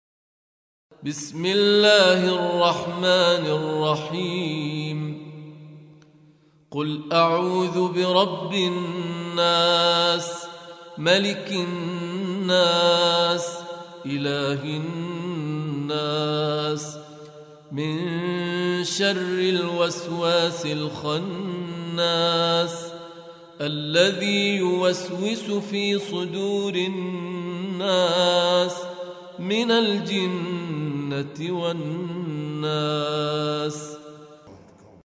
القرآن الكريم - تلاوة وقراءة بصوت أفضل القراء
الاستماع للقرآن الكريم بصوت القارئ